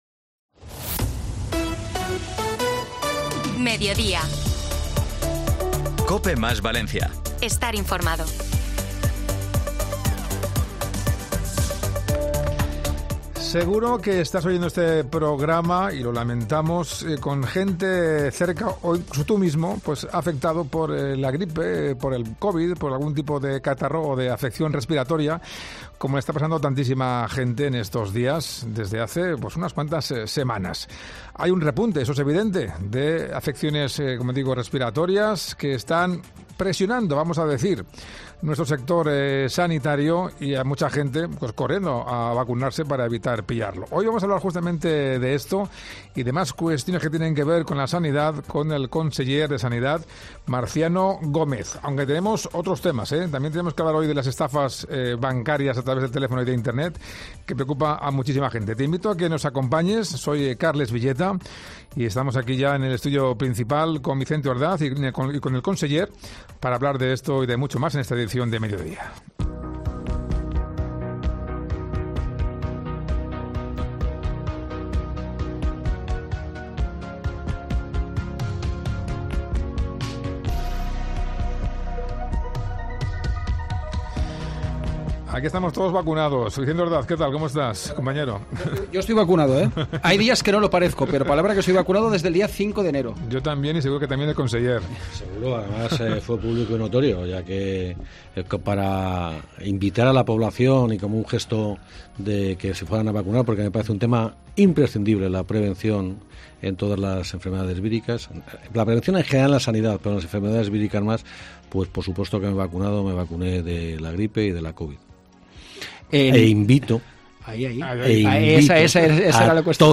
El conseller de Sanidad, Marciano Gómez, ha acudido a COPE Valencia para hablar sobre el plan de Salud Mental para este 2024 y sobre toda la...